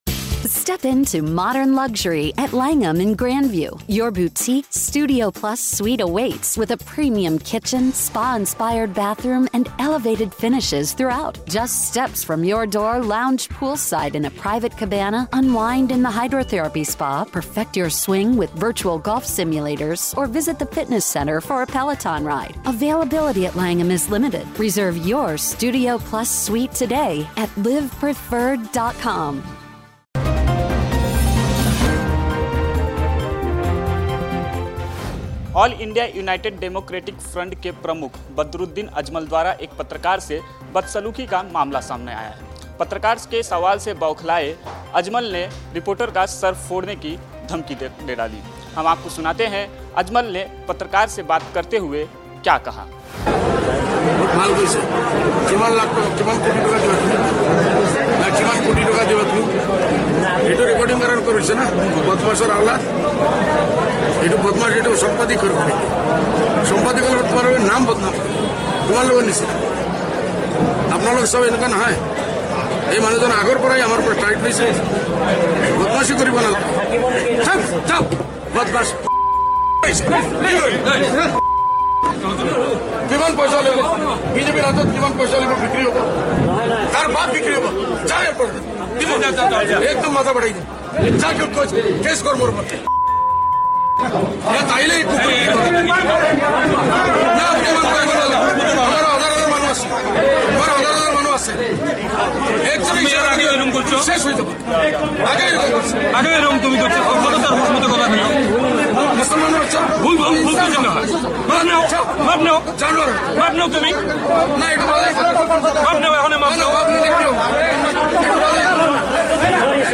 न्यूज़ रिपोर्ट - News Report Hindi / पत्रकार ने पूछा सवाल- सांसद बदरुद्दीन अजमल ने दी सिर फोड़ने की धमकी, मामला दर्ज